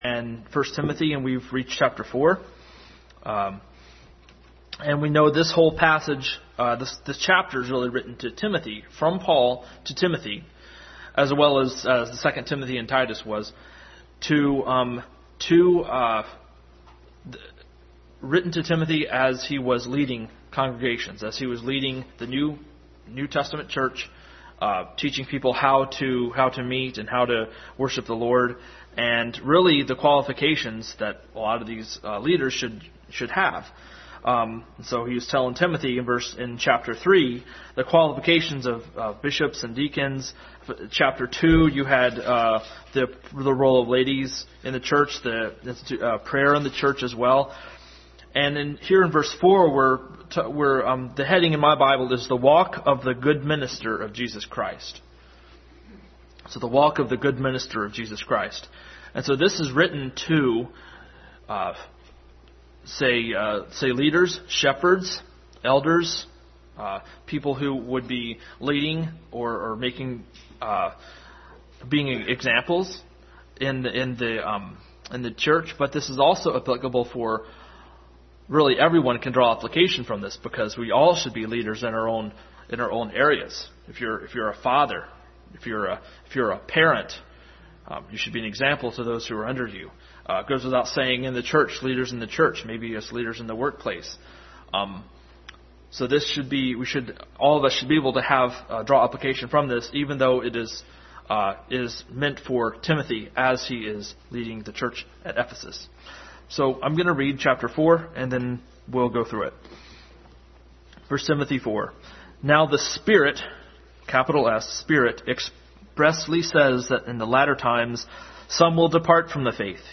Adult Sunday School continued study in 1 Timothy.
1 Timothy 4:1-16 Service Type: Sunday School Adult Sunday School continued study in 1 Timothy.